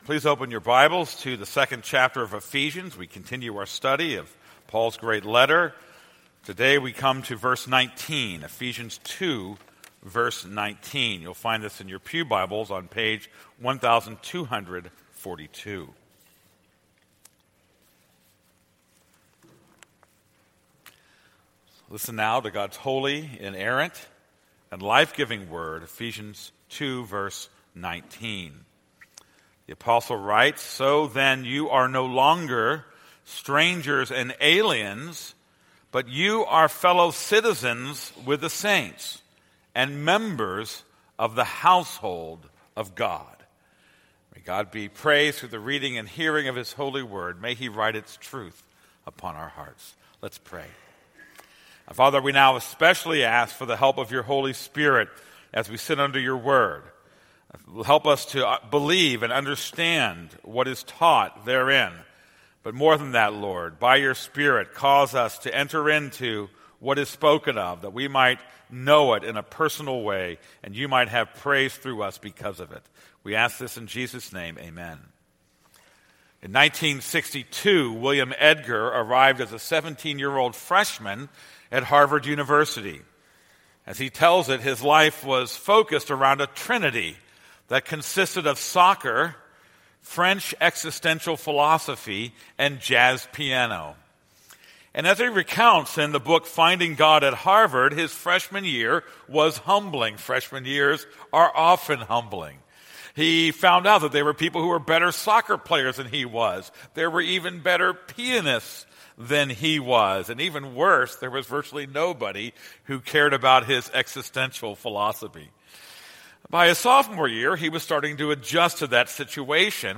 This is a sermon on Ephesians 2:19.